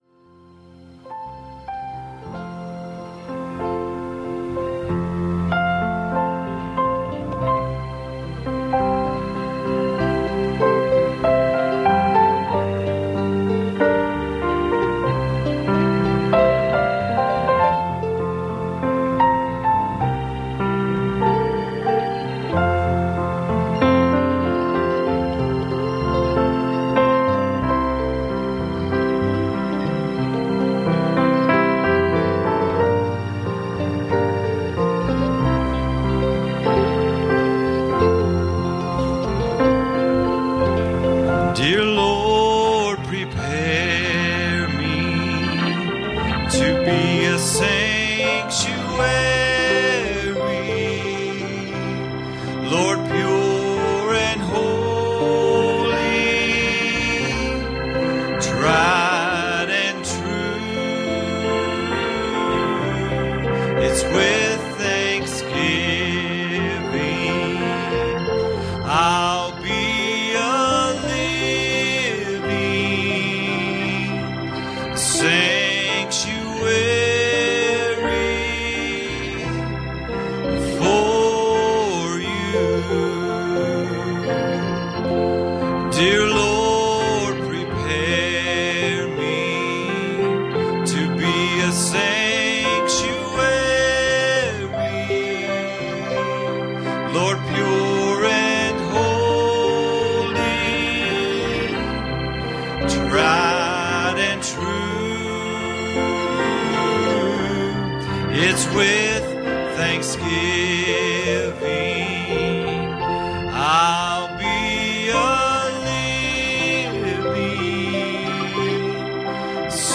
Passage: Isaiah 6:1 Service Type: Youth Service